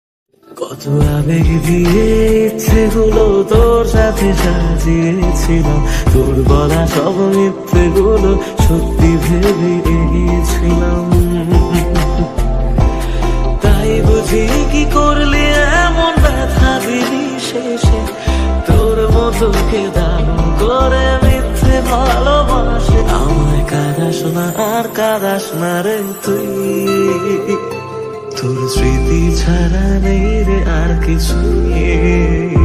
গানটি সুনলে কান্না চলে আসতে পারে😔😥